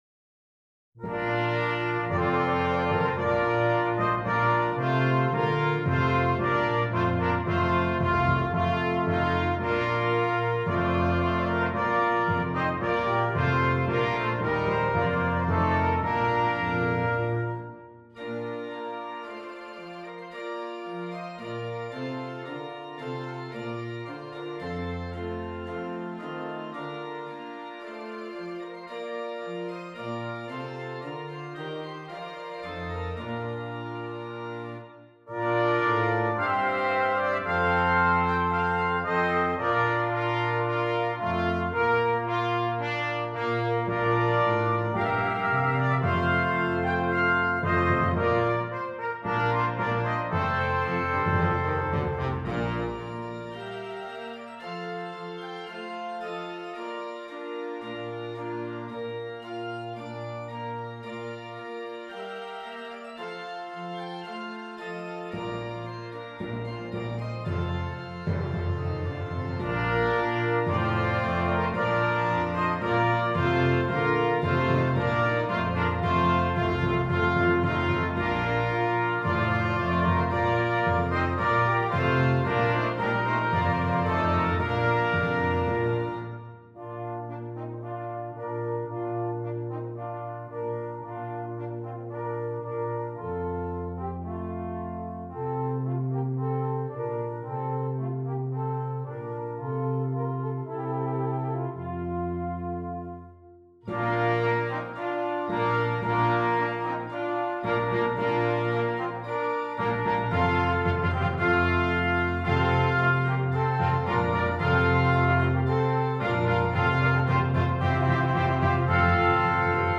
Brass Quintet and Organ